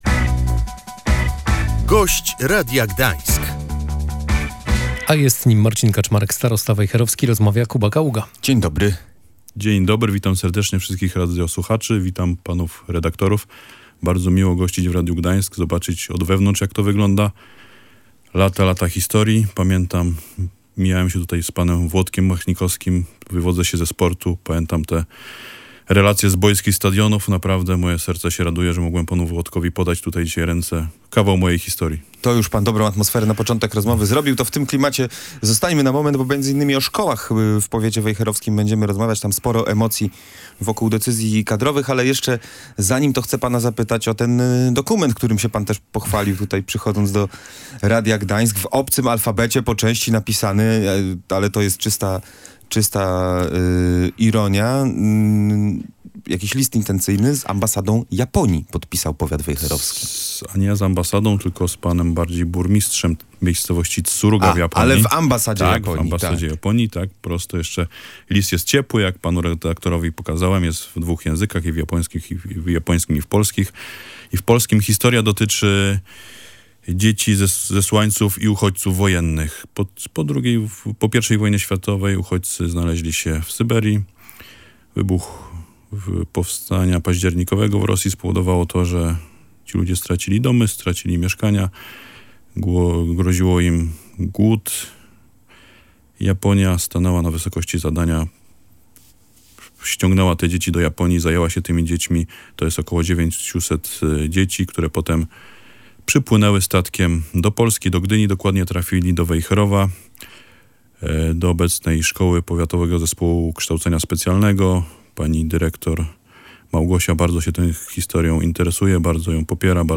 Szkoły ponadpodstawowe w powiecie wejherowskim będą od września działały bez przeszkód - zapewnił na antenie Radia Gdańsk starosta Marcin Kaczmarek.